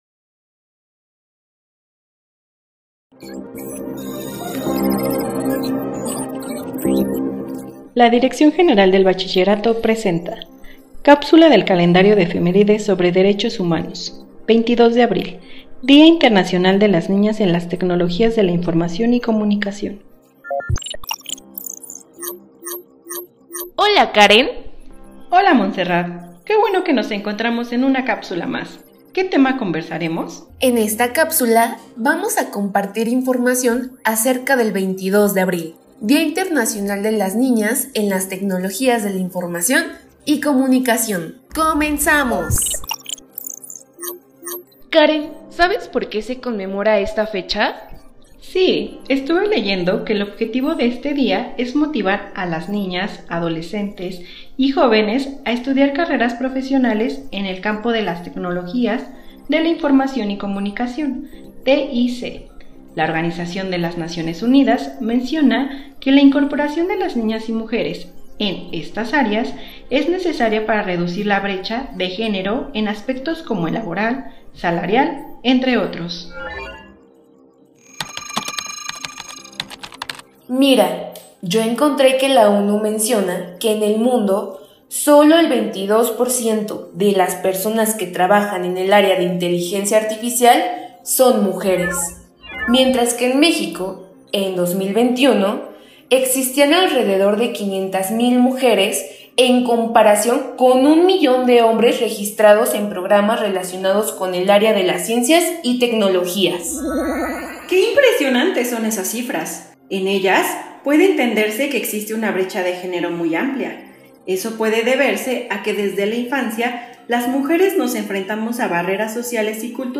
Cápsula de audio informativa